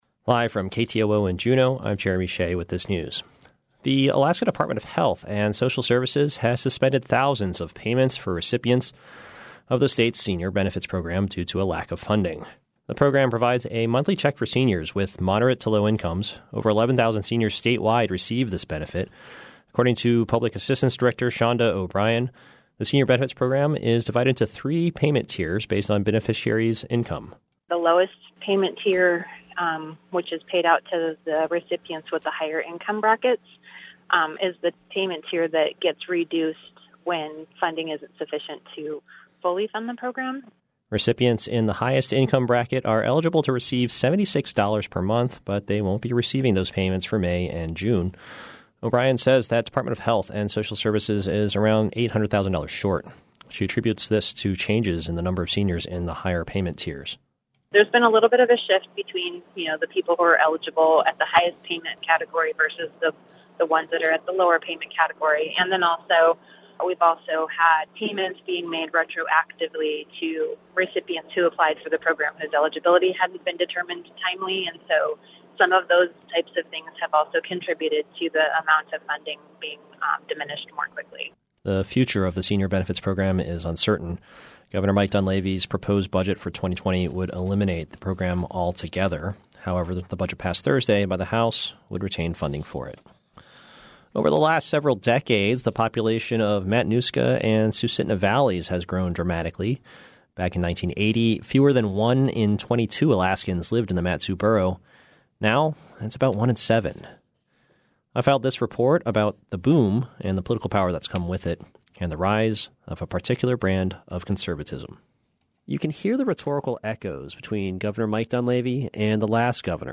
Newscasts